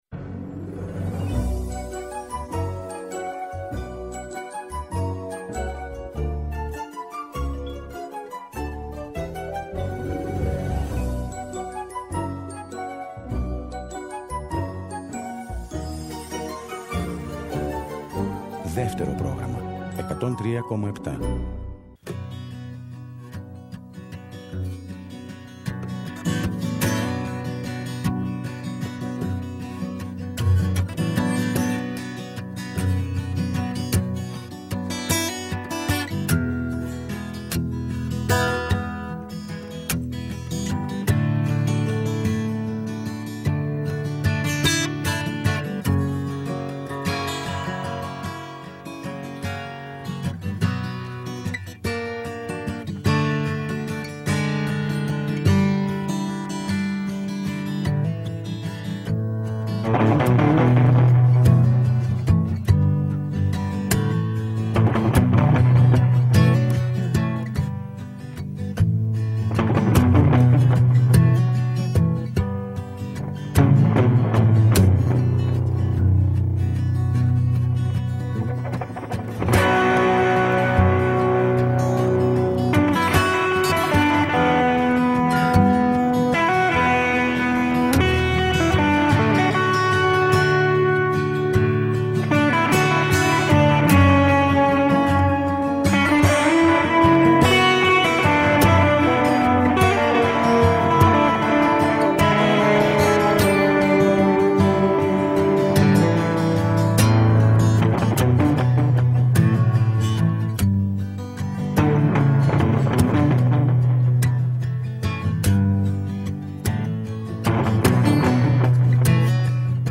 Ακούστε το πρώτο μέρος, από το αρχείο της εκπομπής, τον Philip Glass να μιλά ανάμεσα σε άλλα και για το φιλμ Koyaanisqatsi που χαρακτηρίστηκε έργο – σταθμός στην ιστορία της έβδομης τέχνης.